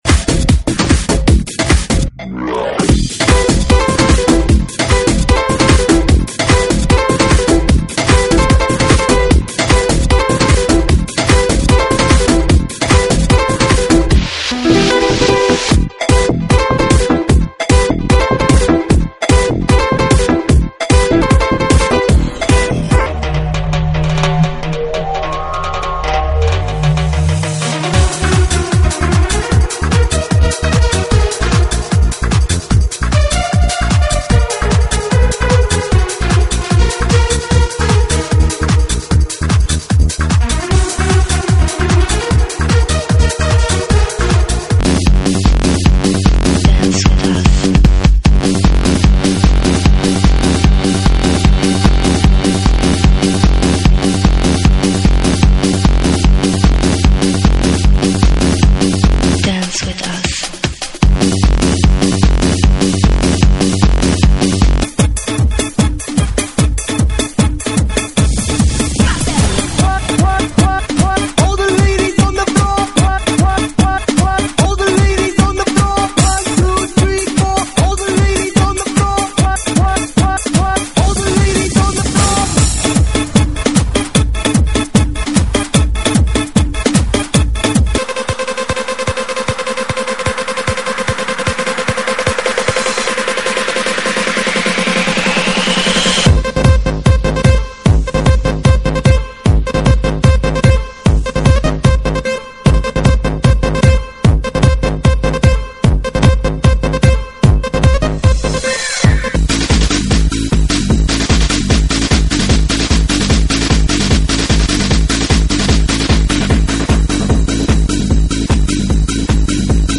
GENERO: ELECTRONICA